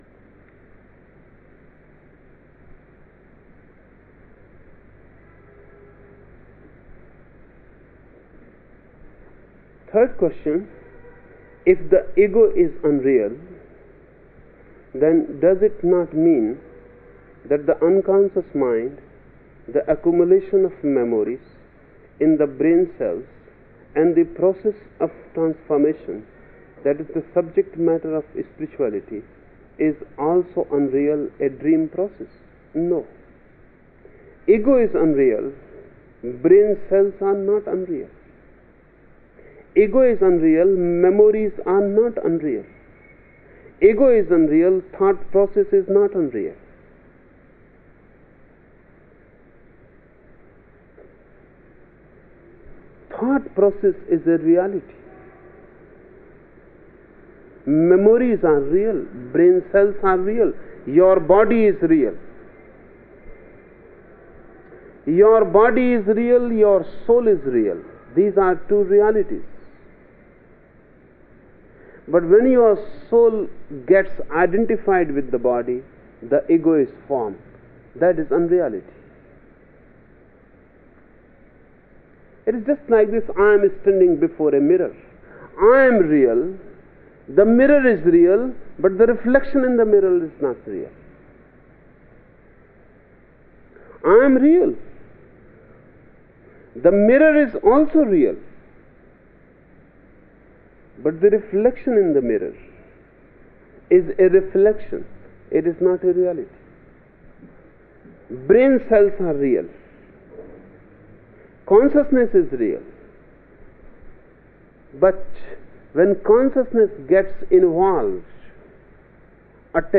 Each program has two parts, Listening Meditation (Osho discourse) and Satsang Meditation.
The Osho discourses in the listening meditations in this module are from the discourse series, The Book of Secrets in which Osho was speaking on Shiva’s 112 meditation techniques, and were given in Mumbai, India from 1972 to 1973.